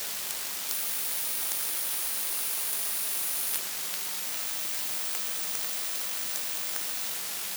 Запись 15-ти канального сигнала системы "Акула"
Начало » Записи » Радиоcигналы классифицированные